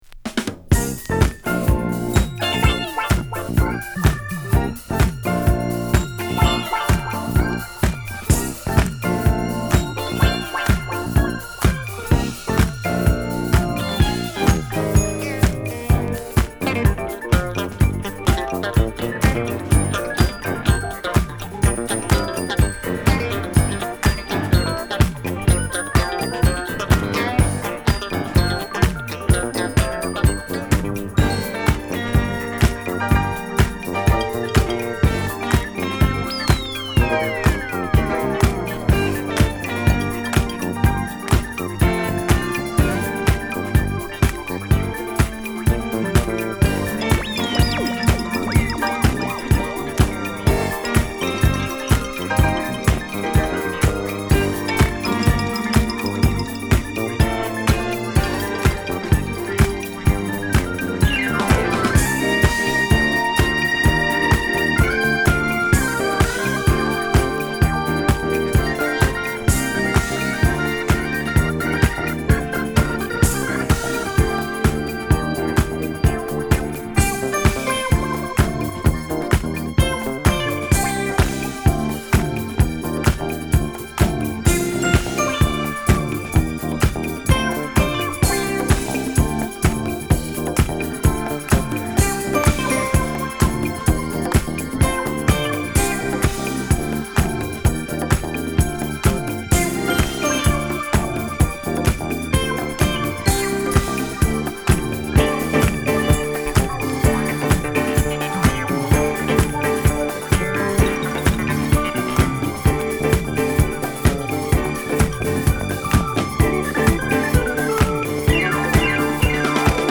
＊試聴はAです。